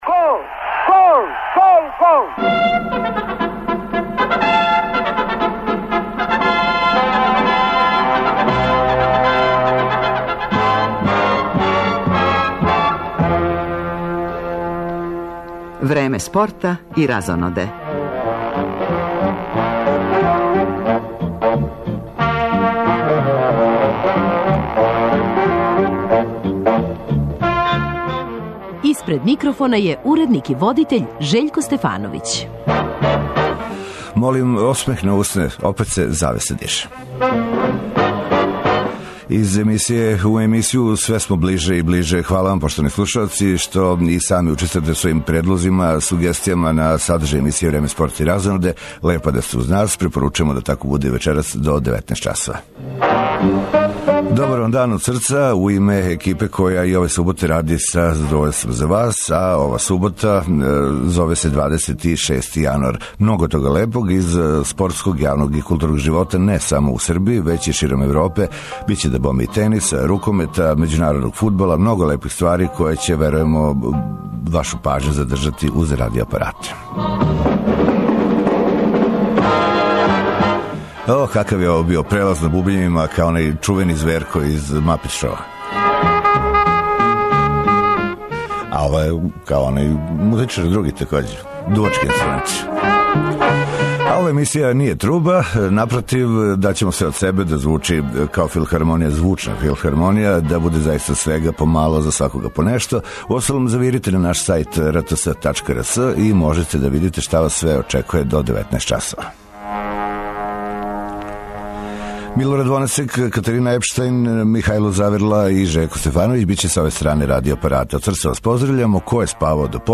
У породичном магазину и данас бројни гости из света спорта и забаве.